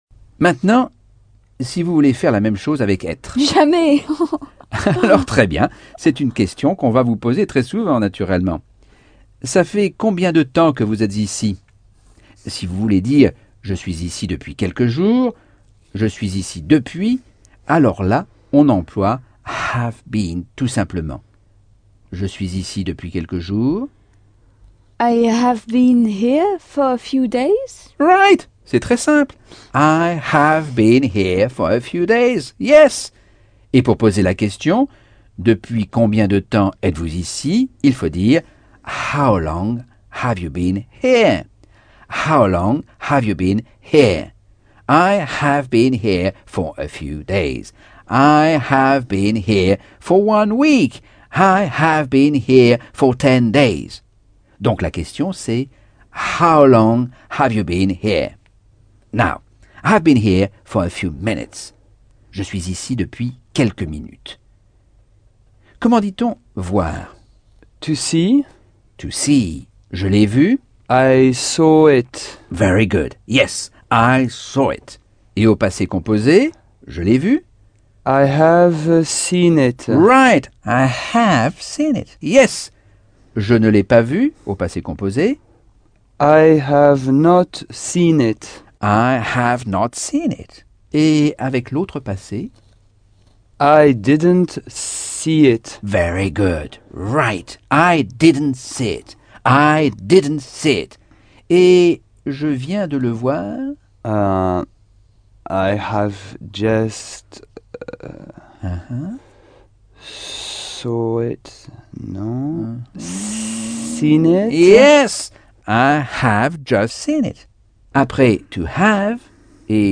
Leçon 3 - Cours audio Anglais par Michel Thomas - Chapitre 9